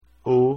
Давайте прослушаем произношение этих звуков:
ô (закрытое “o”):